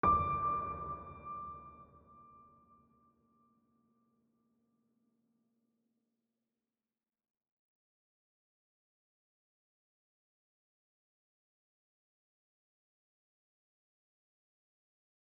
piano-sounds-dev